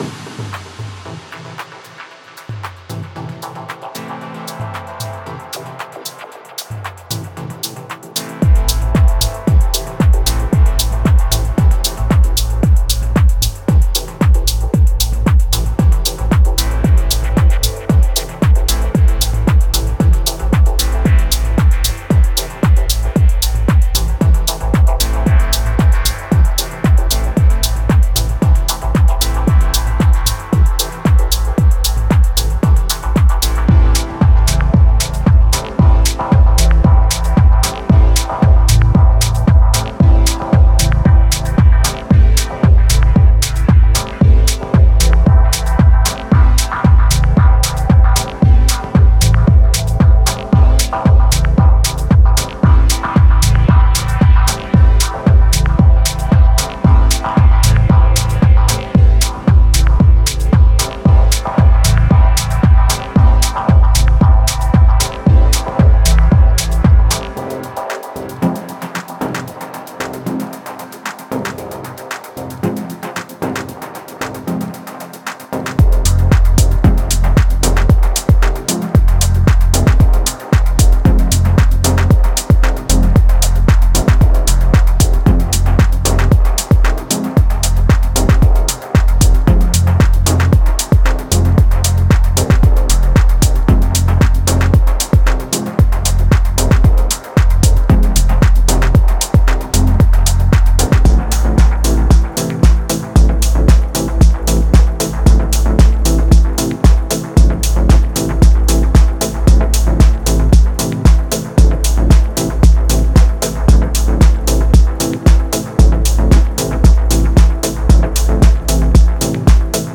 Genre:Dub Techno
このコレクションのドラムループは、シャープなトランジェントと深く転がるようなリズムの絶妙なバランスを実現しています。
ベースループは、深みと共鳴感に満ち、ミックスの中で完璧にフィットする豊かなローエンドを備えています。
デモサウンドはコチラ↓
10 Full Drum Loops 114 Bpm